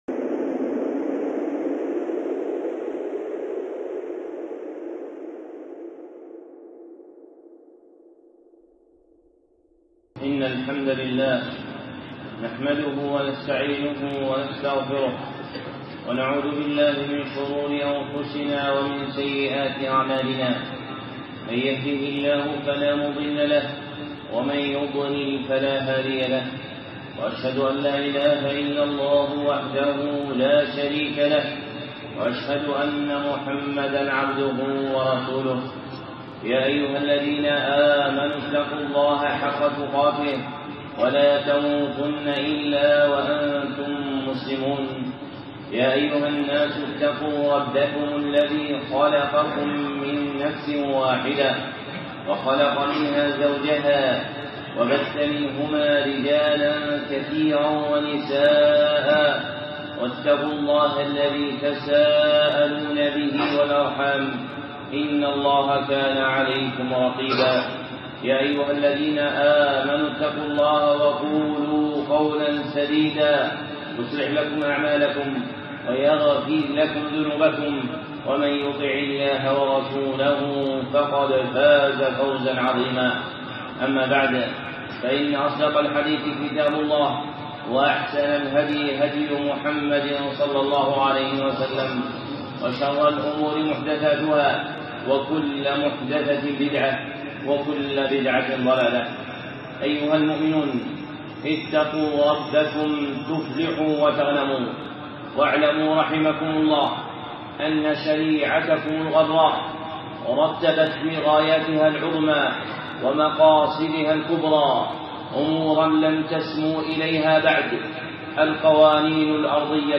خطبة (مقصد حفظ النفس في الدين)
الخطب المنبرية